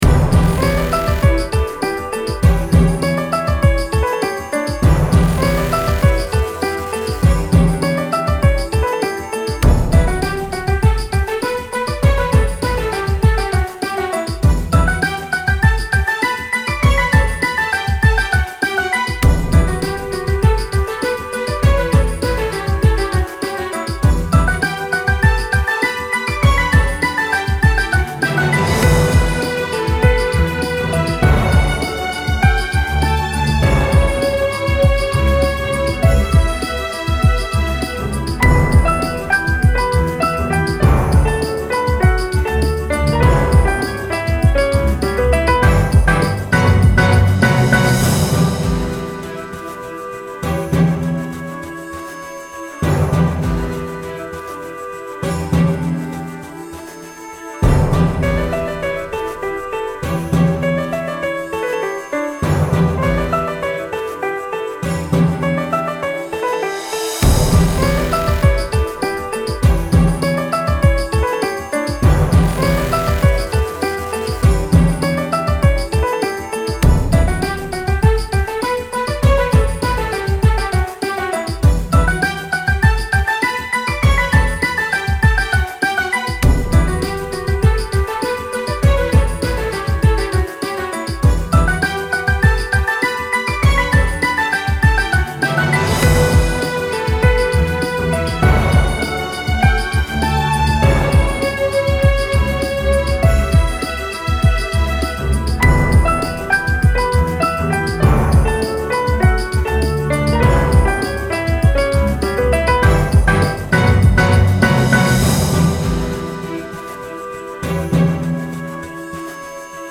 ミステリアス感のある怪しくてかっこいいオーケストラ系のBGMです。 地味にうっすらシンセやドラムなども入っています。